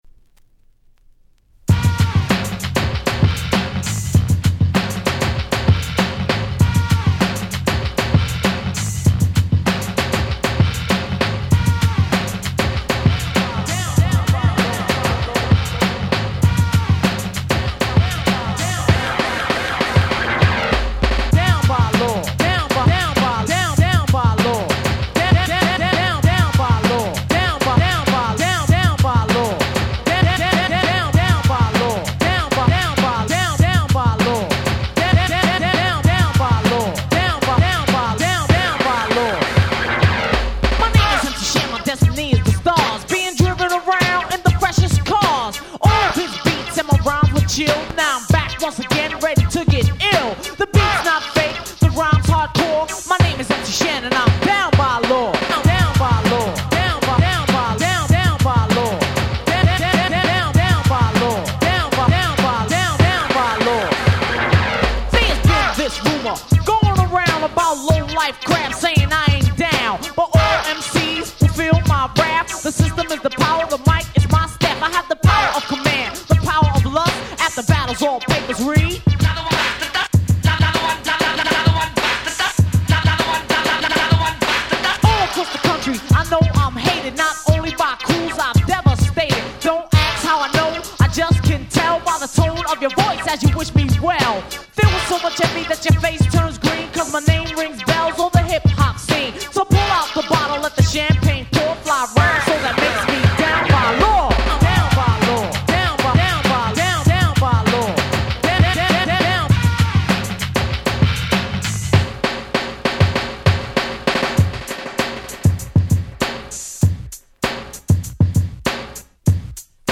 87' Super Hip Hop Classic !!